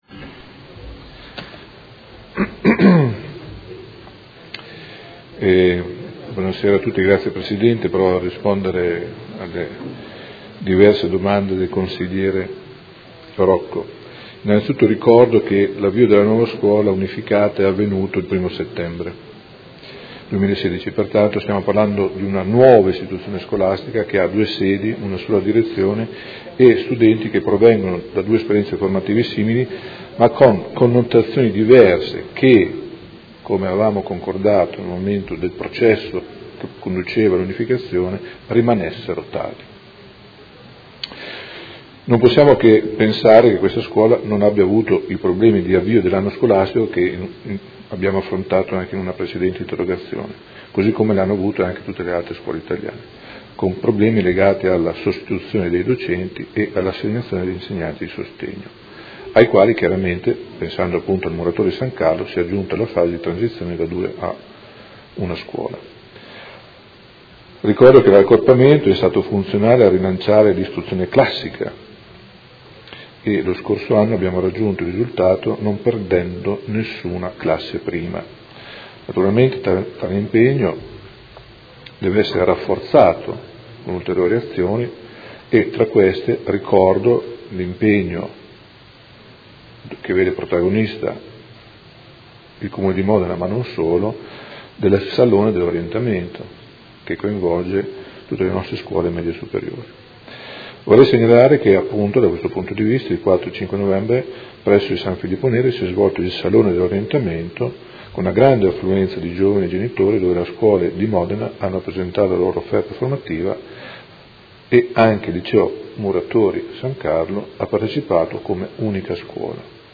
Seduta del 24/11/2016 Risponde. Interrogazione del Consigliere Rocco (FAS-SI) avente per oggetto: Licei classici San Carlo e Muratori, promesse tradite sul mantenimento della parità